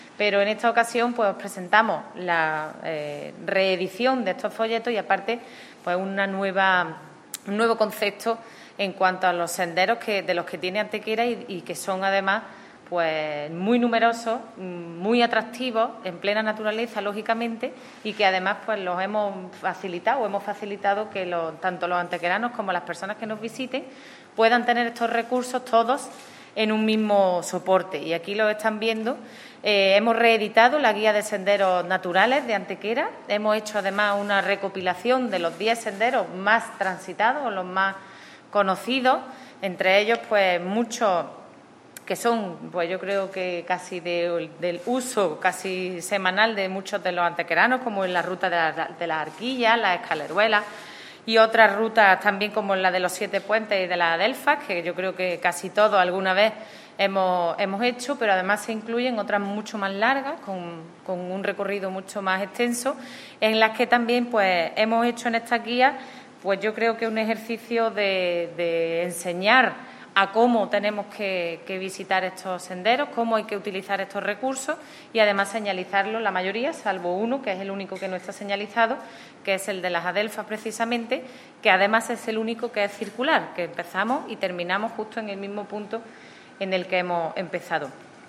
La teniente de alcalde delegada de Turismo, Ana Cebrián, ha presentado en la mañana de hoy los nuevos folletos editados por el Ayuntamiento para promocionar y poner en valor la Red de Senderos de Antequera, que agrupa a diez de los itinerarios más atractivos y conocidos de nuestro municipio.
Cortes de voz